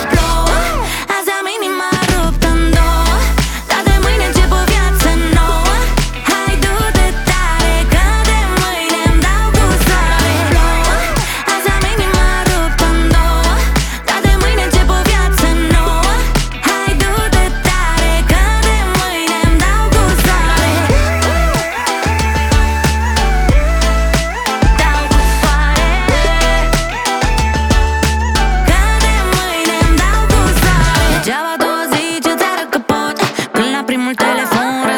2025-05-29 Жанр: Поп музыка Длительность